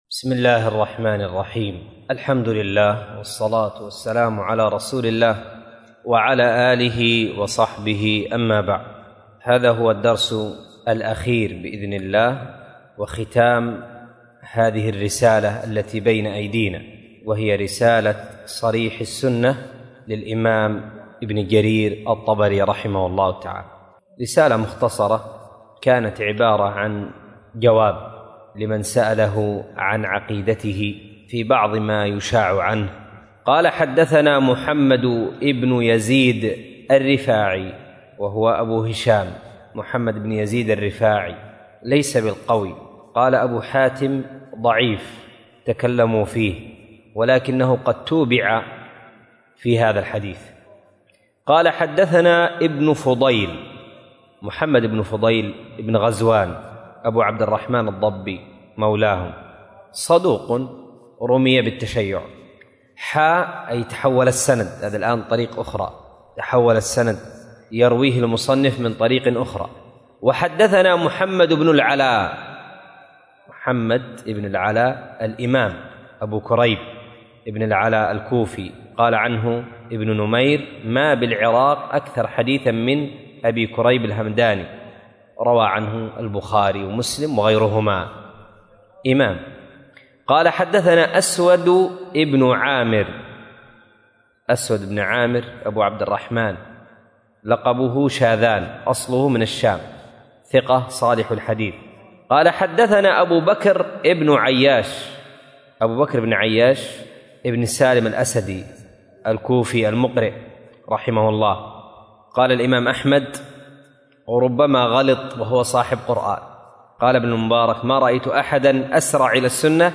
الدرس ( 26 والأخير ) من شرح صريح السنة للإمام المفسر ابن جرير الطبري.mp3